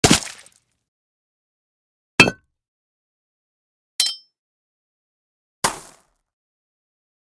Various Impacts
Various Impacts is a free sfx sound effect available for download in MP3 format.
Various Impacts.mp3